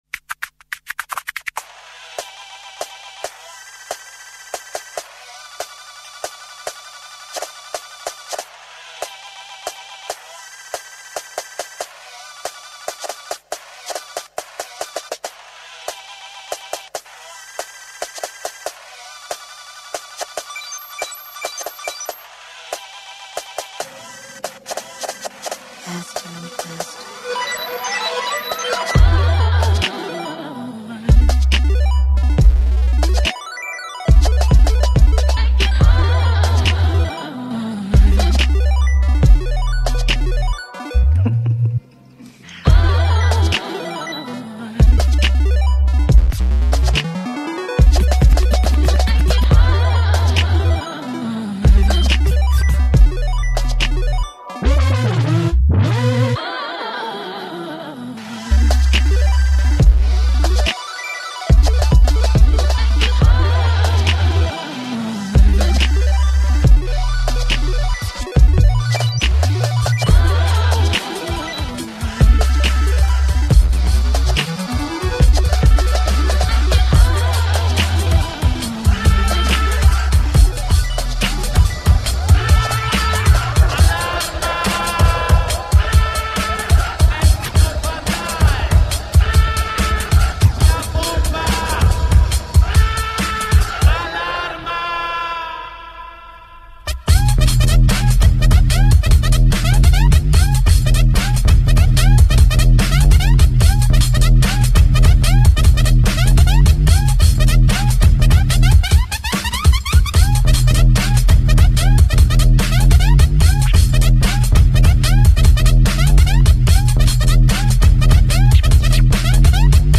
Dubstep and Bassline